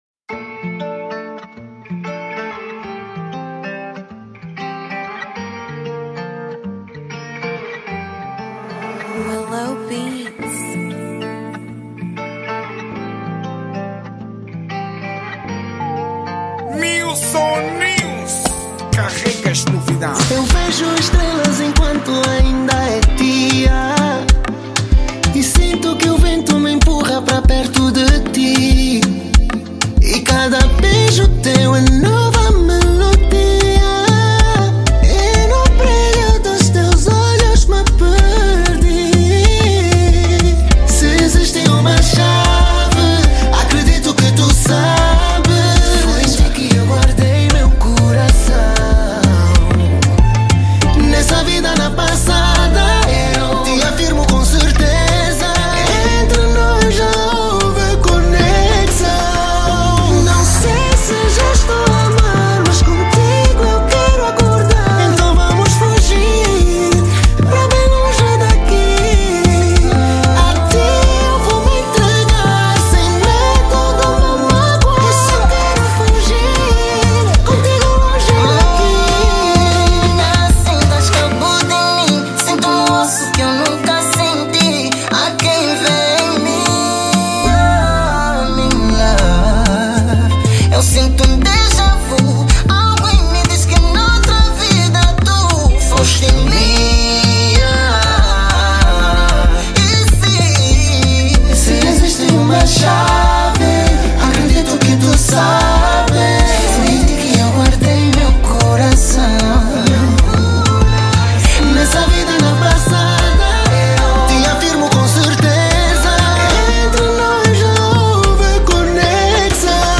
| Afro Music